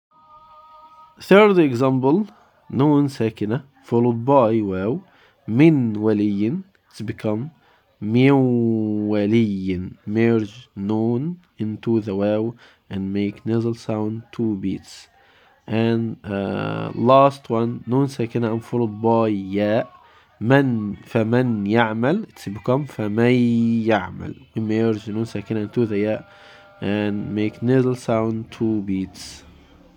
• The Source of the Sound: The Ghunnah you hear during the merge is actually the trace of the original Noon (the Mudgham), not the following letter.
Examples-of-Incomplete-Idgham-with-Ghunnah.mp3